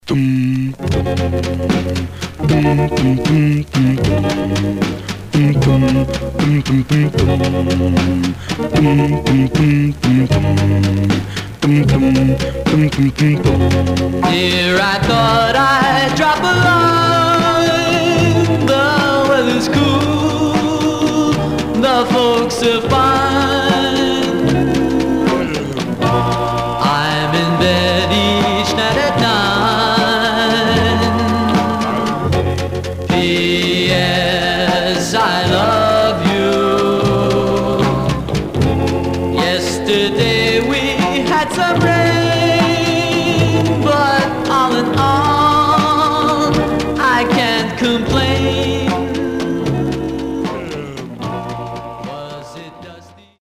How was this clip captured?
Some surface noise/wear Stereo/mono Mono